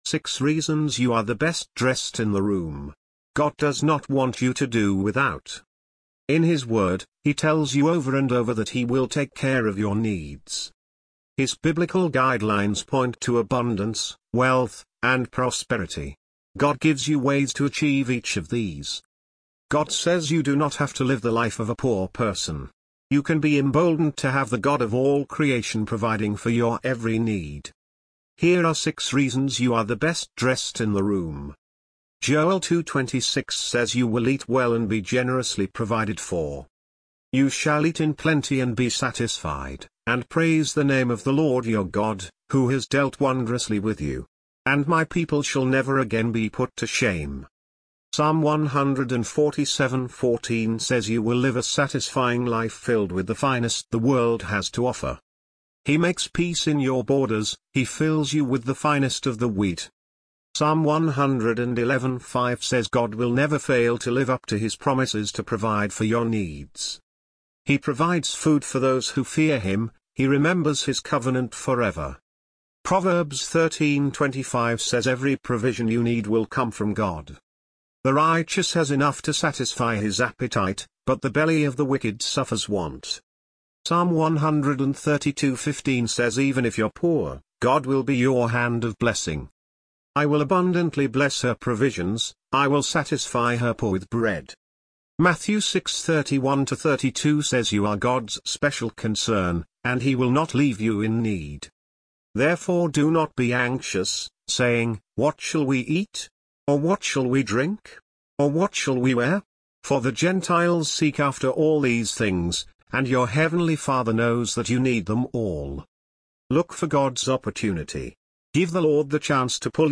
English Audio Version